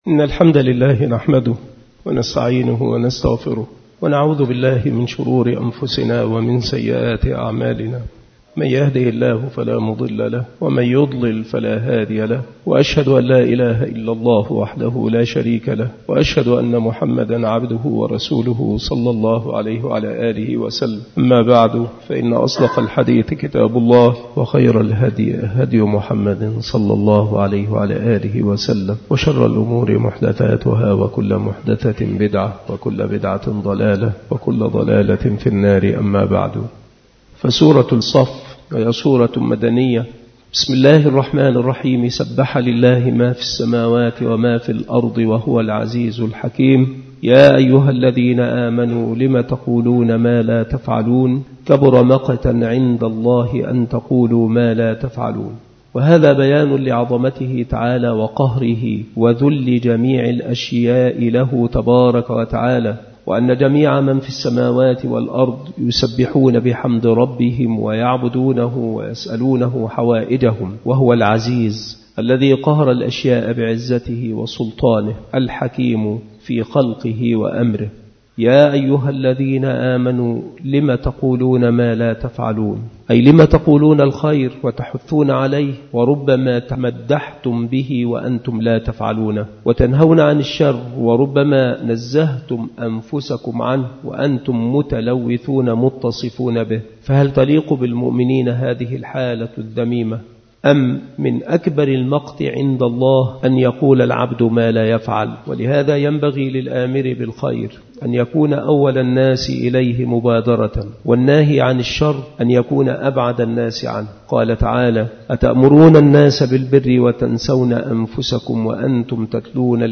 التفسير
مكان إلقاء هذه المحاضرة بالمسجد الشرقي بسبك الأحد - أشمون - محافظة المنوفية - مصر